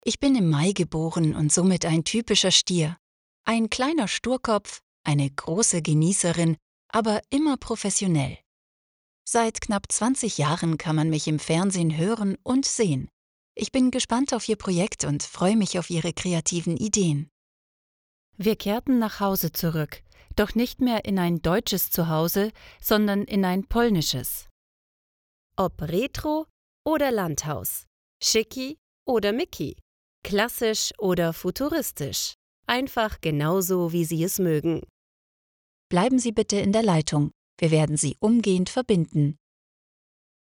Commercial Demo
My voice is powerful, professional and pleasant.
Neumann TLM 102
LowMezzo-Soprano
AccurateWarmCorporateReliableExperienced